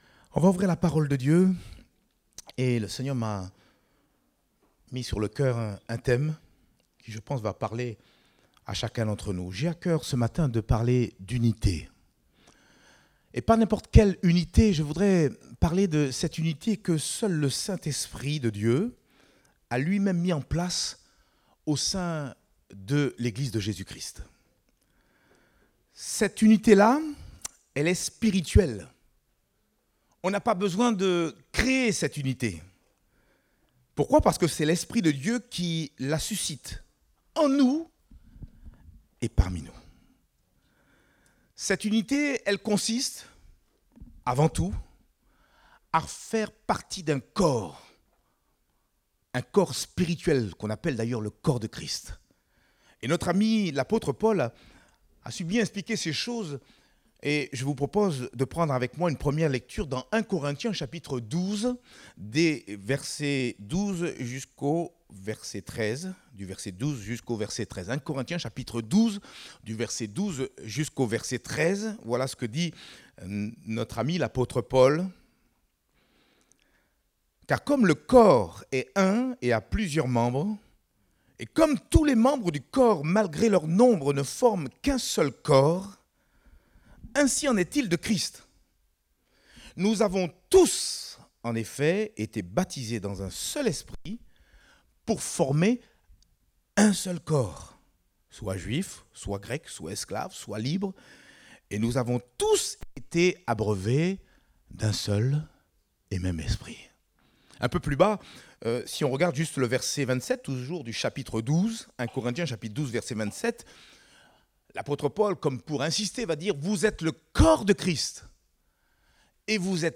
Date : 18 février 2024 (Culte Dominical)